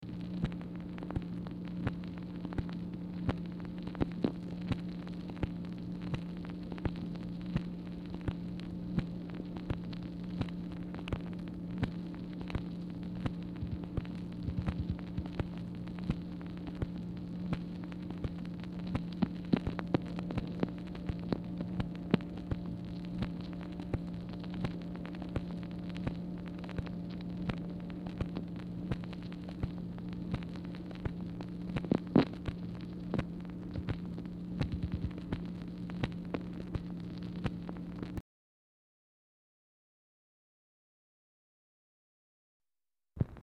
Telephone conversation # 3115, sound recording, MACHINE NOISE, 4/23/1964, time unknown | Discover LBJ
Format Dictation belt